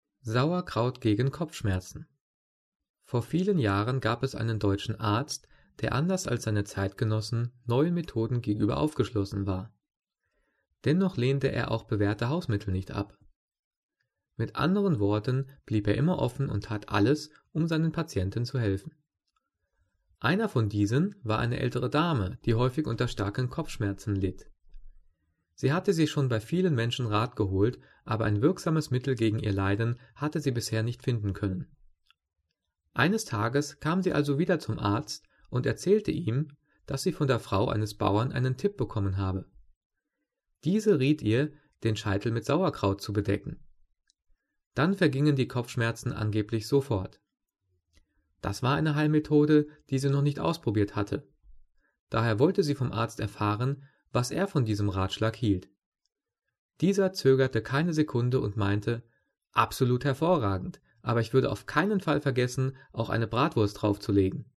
Gelesen:
gelesen-sauerkraut-gegen-kopfschmerzen.mp3